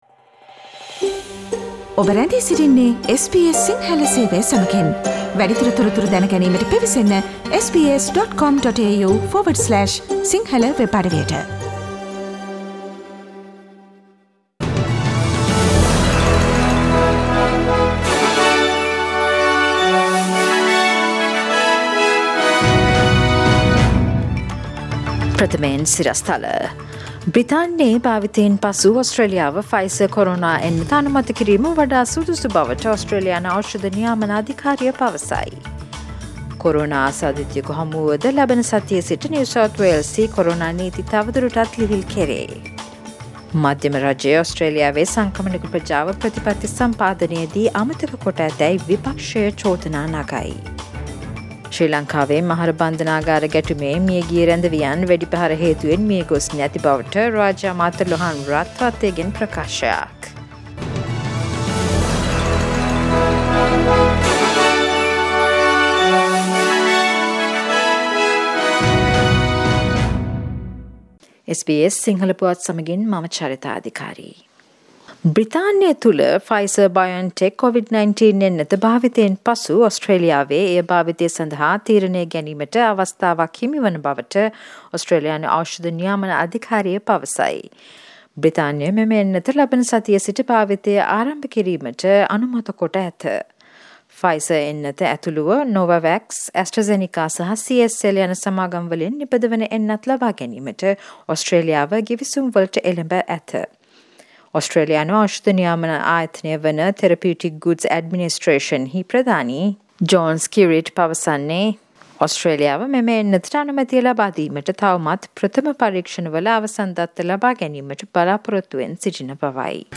Today’s news bulletin of SBS Sinhala radio – Friday 4 December 2020.